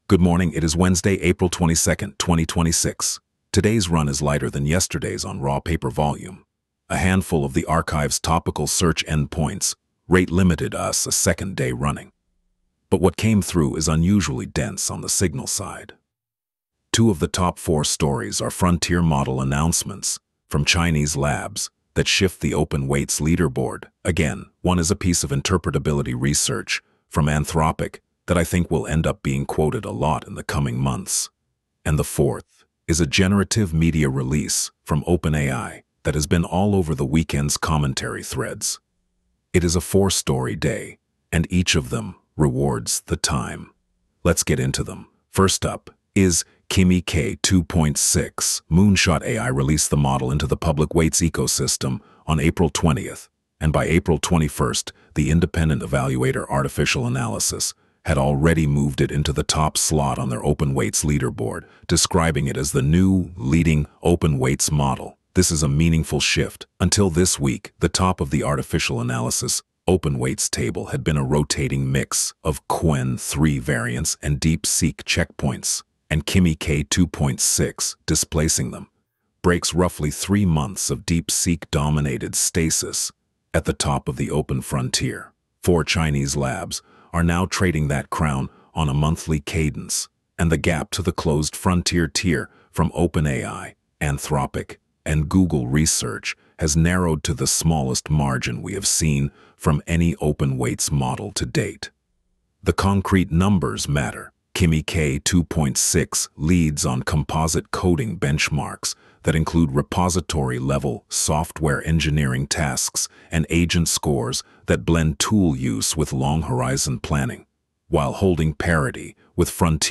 Wolf Digest — 2026-04-22 Coverage window: 2026-04-21 03:27 ET → 2026-04-22 03:02 ET ▶ Press play to listen Wednesday, April 22, 2026 14m 1s · top-4 narrated briefing Subscribe Apple Podcasts Spotify Download MP3